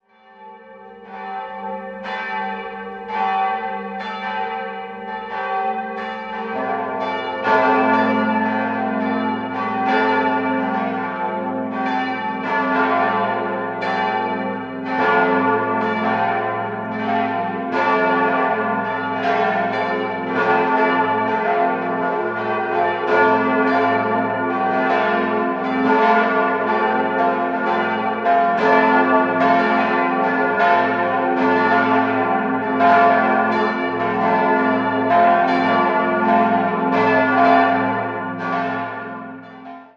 4-stimmiges Geläute: a°-f'-g'-a'
Antoniusglocke
Josefsglocke
Wolfgangsglocke
Die Kirche besitzt das zweittontiefste Geläute Regensburgs nach dem Dom.
Dadurch besitzt die Kirche nun ein unvollständiges Geläute, es hat aber aufgrund der enormen Tonlücke zwischen den Glocken 1 und 2 einen hohen Wiedererkennungswert.